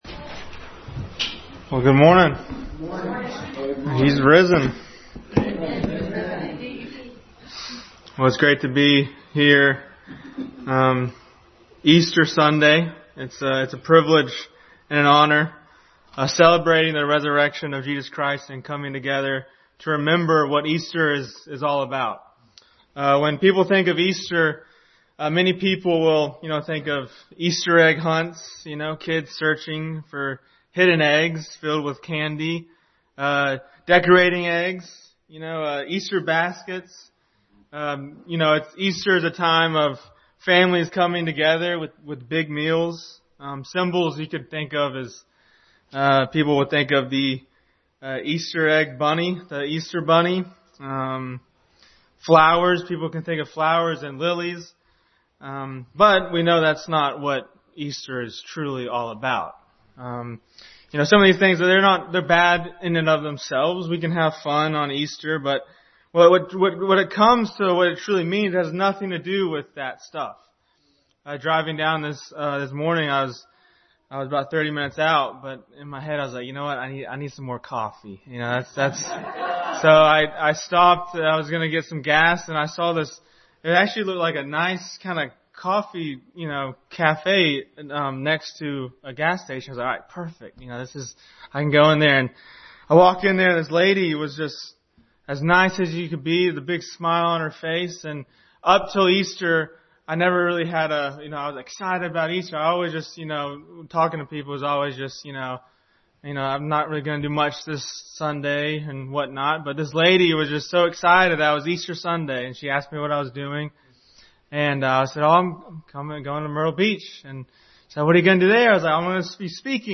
Why the Resurrection Matters Passage: 1 Corinthians 15 Service Type: Sunday School